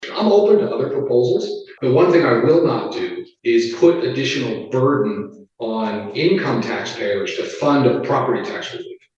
At a press conference at the state capital today, Governor Greg Gianforte said he remained optimistic that his income tax cut and property tax relief proposals can be revived despite rejection by the Senate Taxation Committee. The Governor noted he is willing to listen to other property tax proposals but laid down one important criterion that must be met.